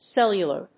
音節cel・lu・lar 発音記号・読み方
/séljʊlɚ(米国英語), séljʊlə(英国英語)/